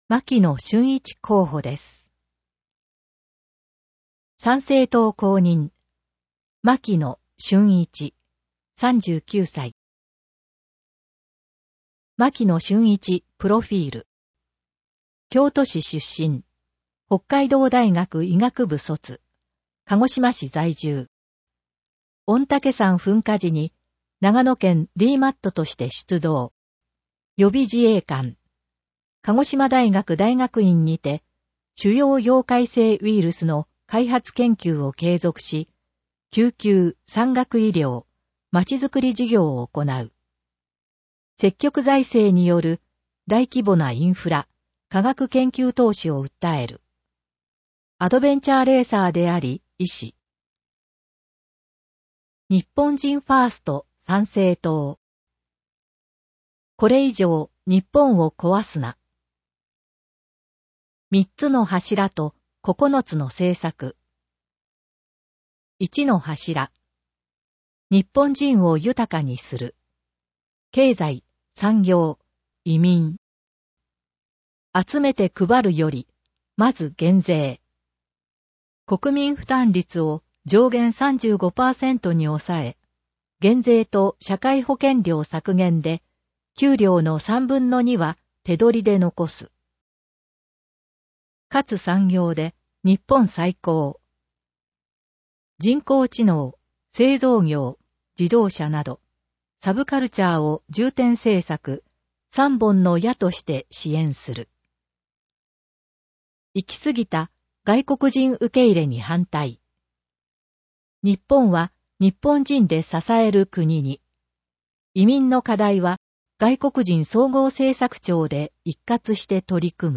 音声読み上げ対応データ（MP3：1026KB）